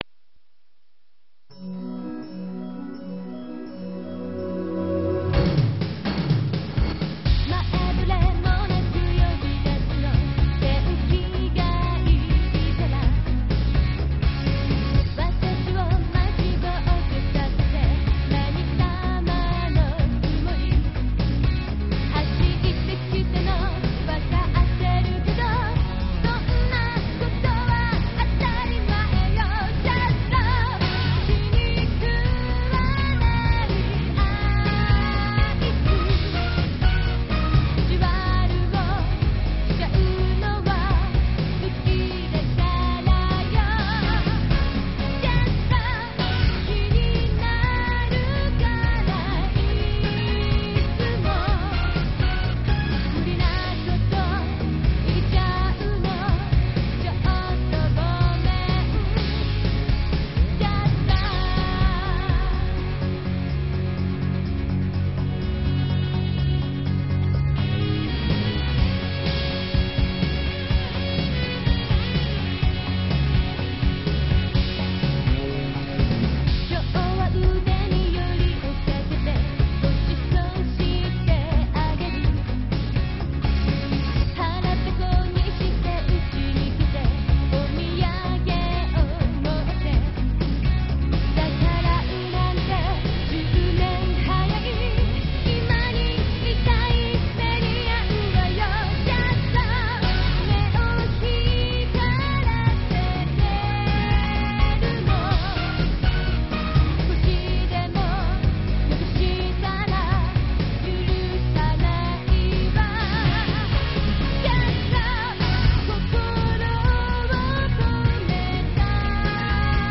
32Kbps, 44khz, Mono